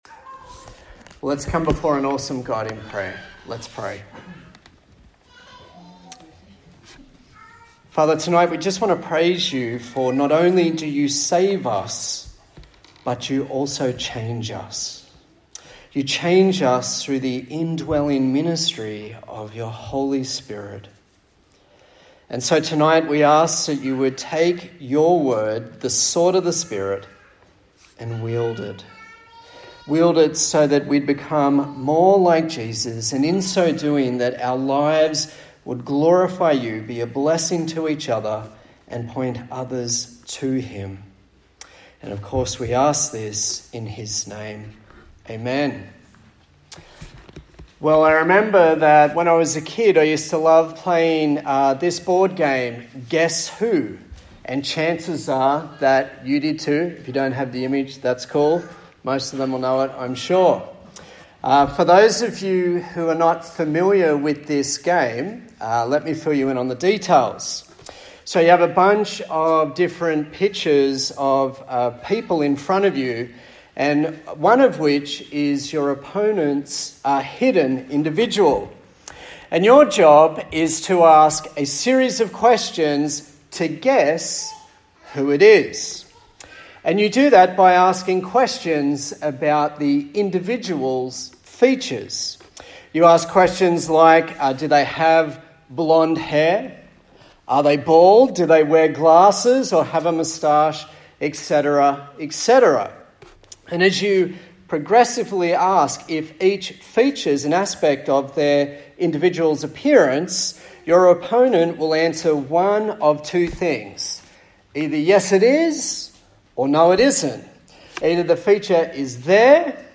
A sermon in the series on the book of Galatians